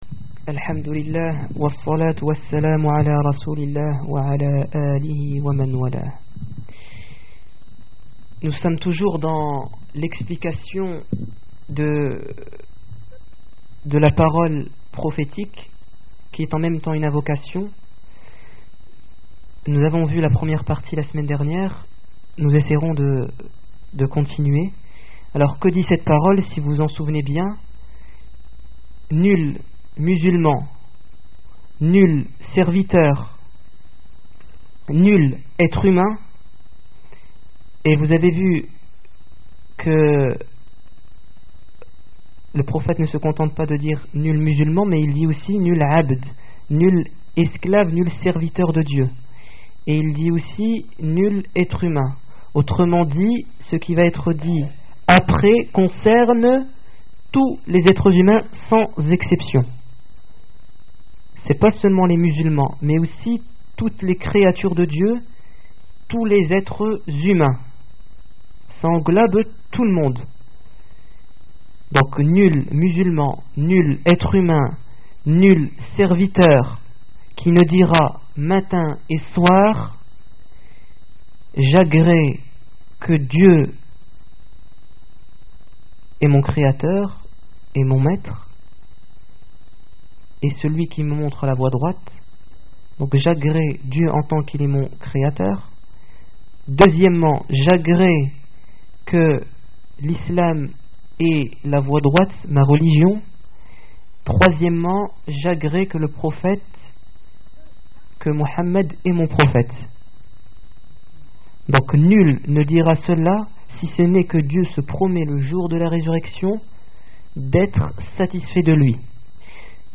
Discours du 27 juillet 2007
Discours du vendredi